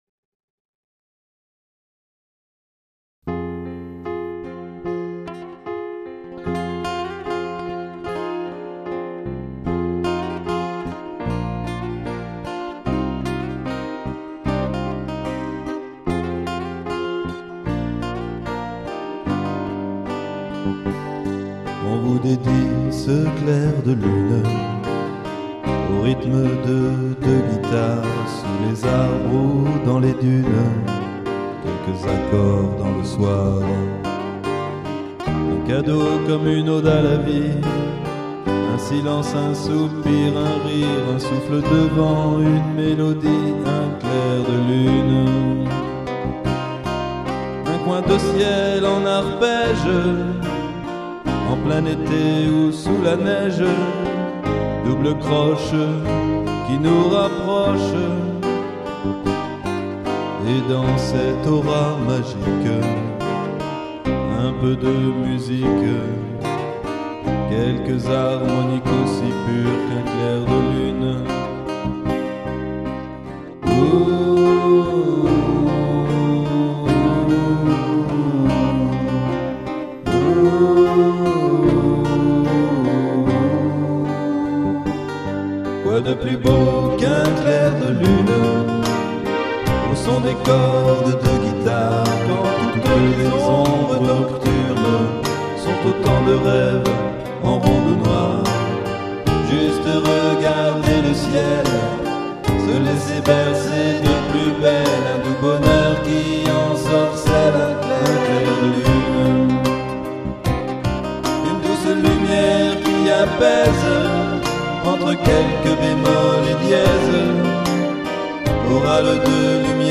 version originale 2001